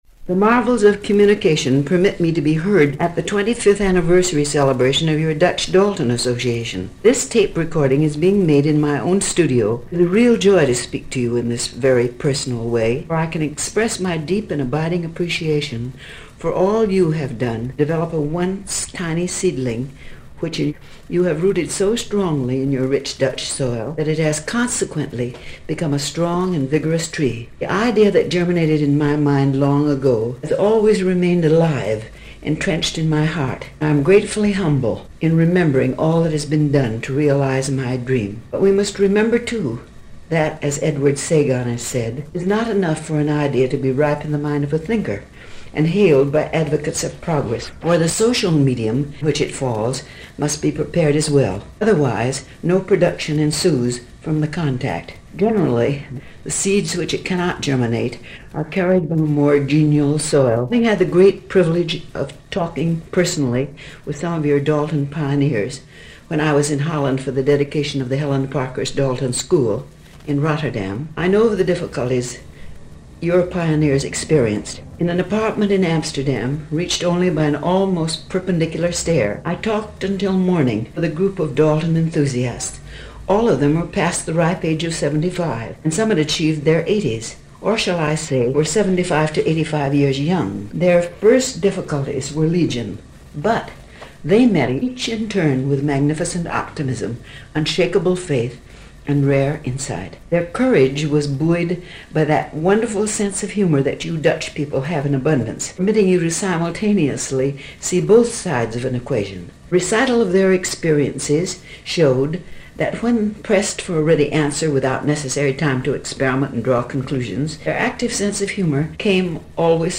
To mark the 25th anniversary of the Dutch Dalton Association, Helen Parkhurst recorded a congratulatory message on a gramophone record in her own study. The recording has been digitized and can be listened to below: